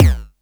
Index of /musicradar/essential-drumkit-samples/Chip Tune Kit
ChipTune Kick 03.wav